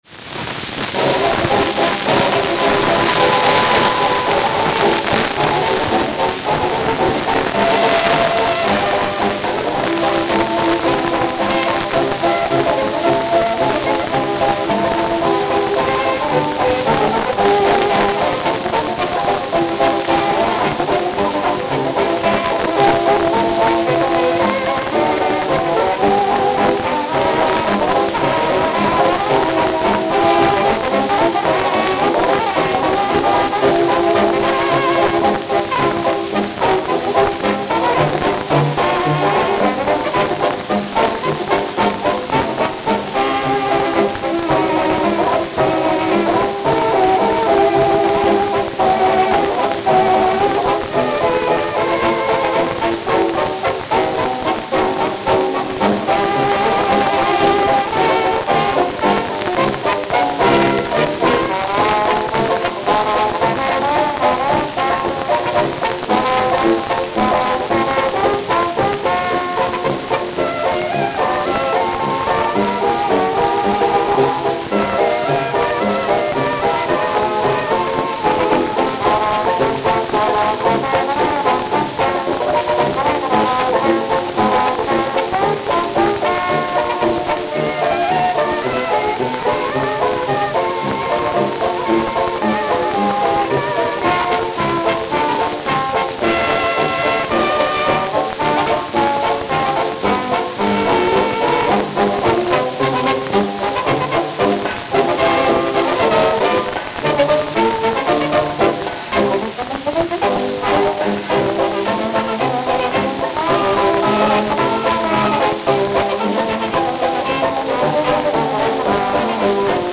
Note: Extremely worn.